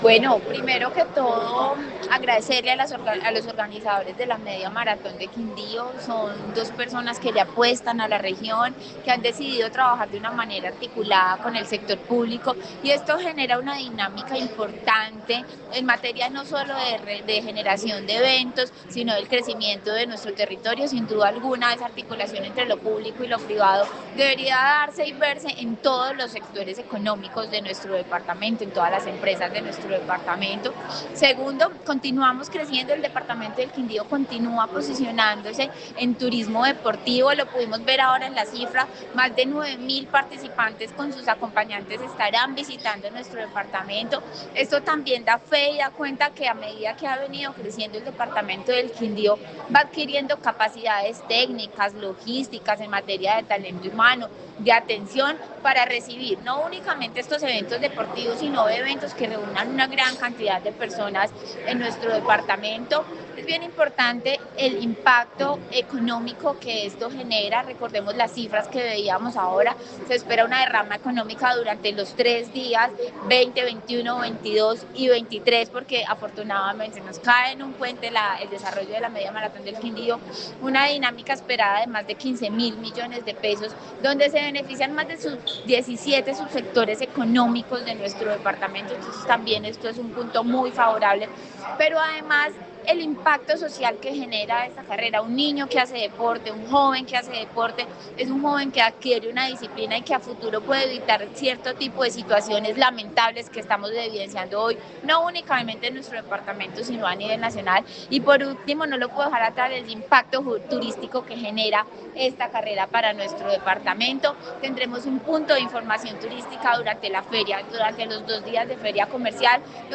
Audio de Juana Camila Gómez, secretaria de Turismo, Industria y Comercio (MMQ).